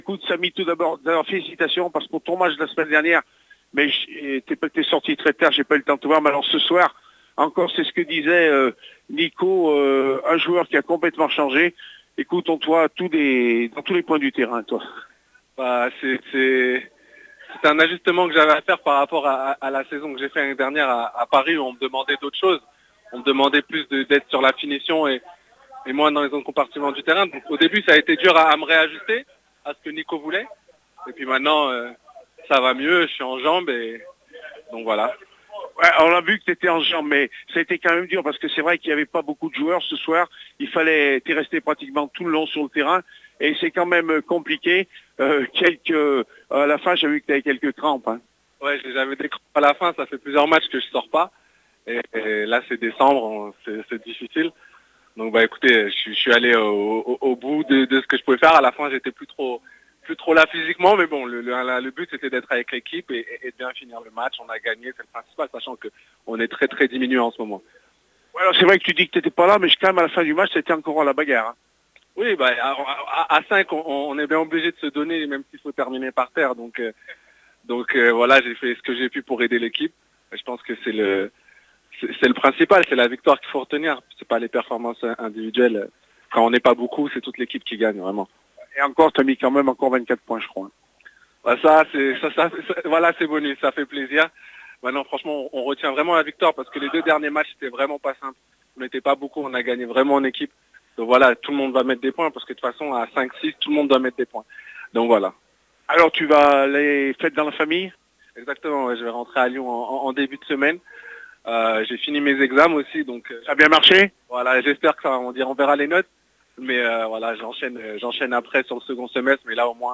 MATCH DE BASKET ASMB LE PUY-USA TOULOUGES 82-77 NATIONALE 2 APRES MATCH
REACTIONS APRES MATCH